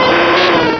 Cri d'Arcanin dans Pokémon Rubis et Saphir.